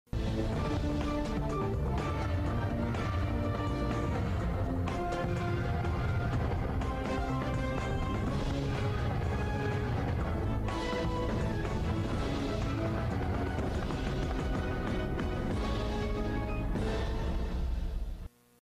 Station Ident